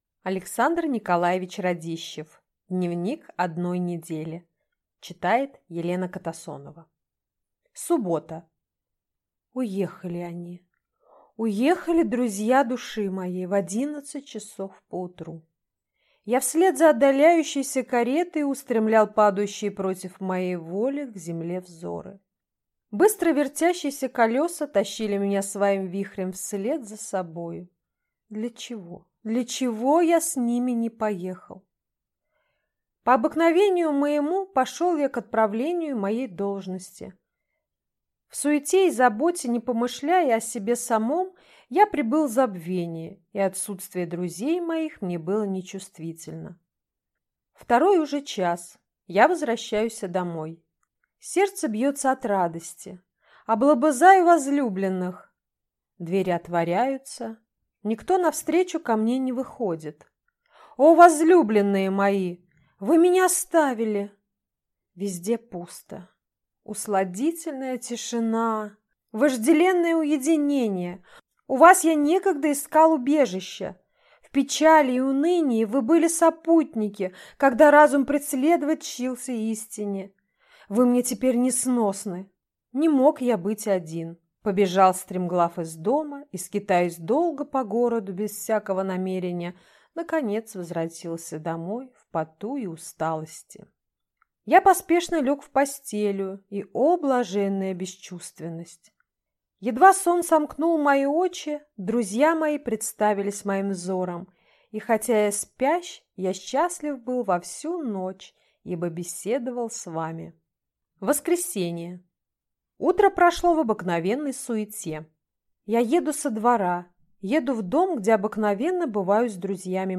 Aудиокнига Дневник одной недели